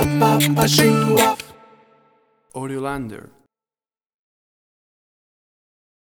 WAV Sample Rate: 16-Bit stereo, 44.1 kHz
Tempo (BPM): 156